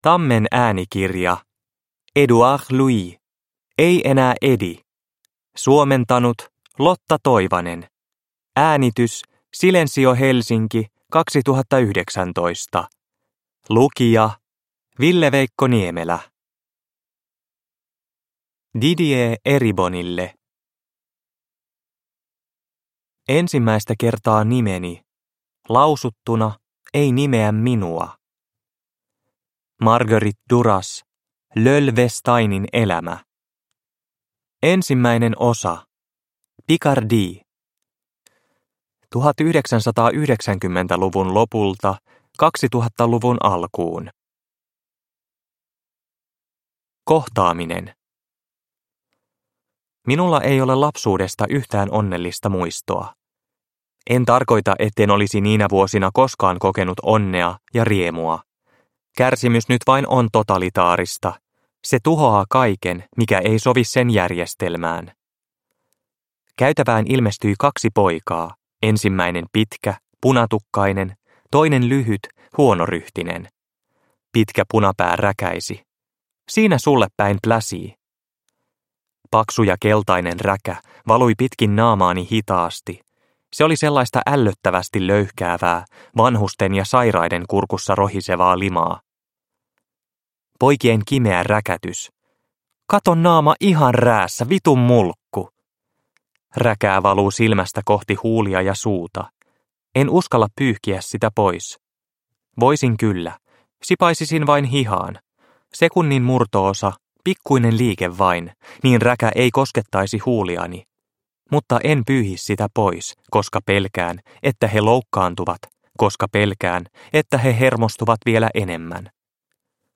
Ei enää Eddy – Ljudbok – Laddas ner